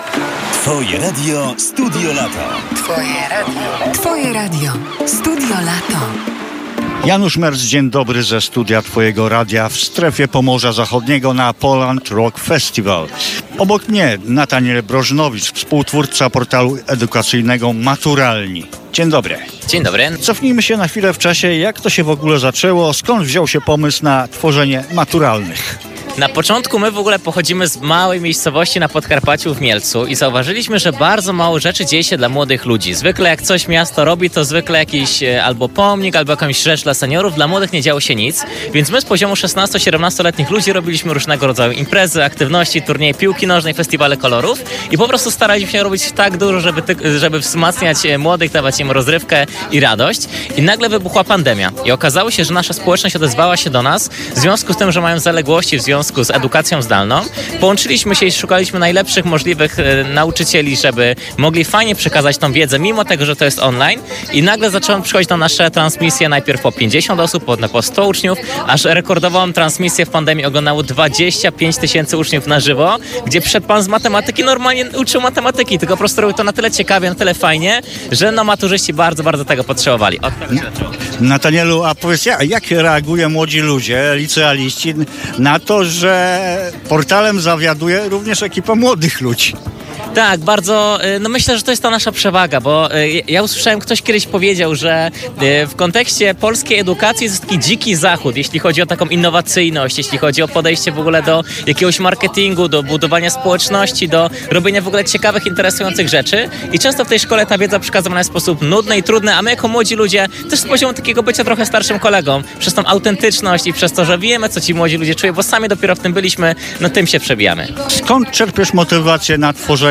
Publikujemy wywiady, nagrane przez reporterów Twojego Radia bezpośrednio w Strefie Pomorza Zachodniego.